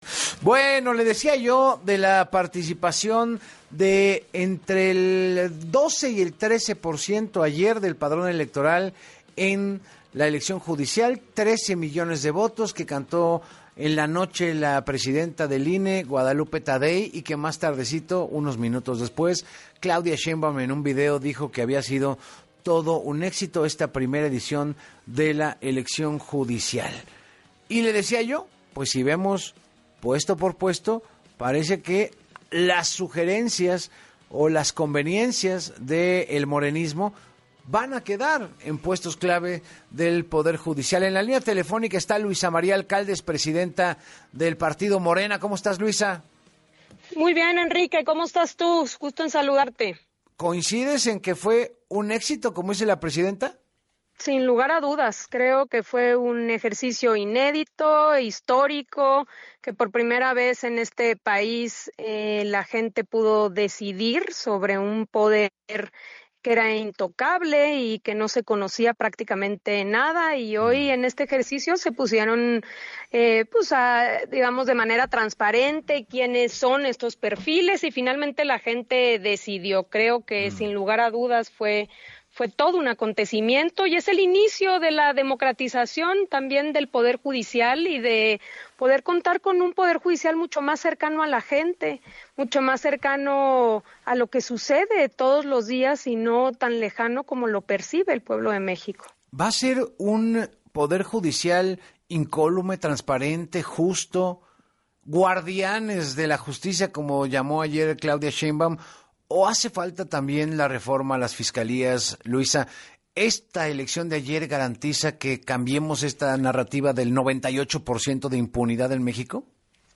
En entrevista para Así Las Cosas con Enrique Hernández Alcázar, la dirigente nacional de Morena, Luisa María Alcalde, destacó que “13 millones es un número importante, sobre todo porque no había elecciones concurrentes” y tenía sus complejidades.